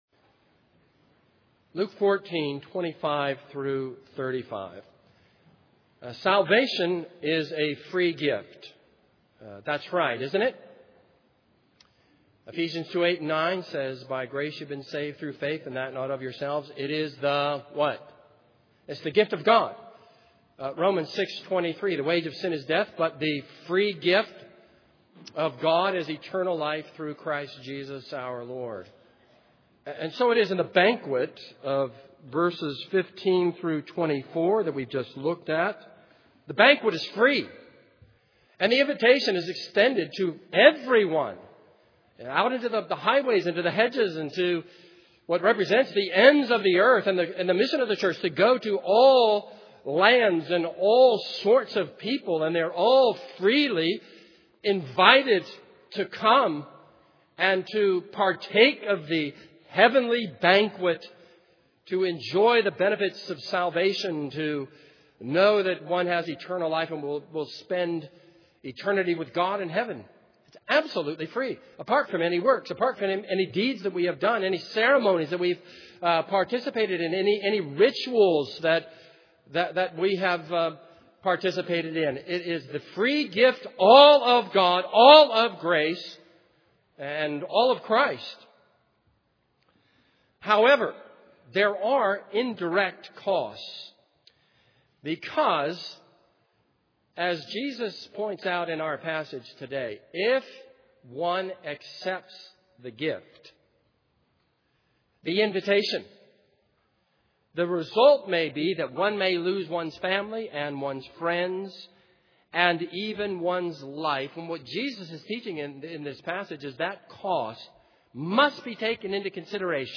This is a sermon on Luke 14:25-35.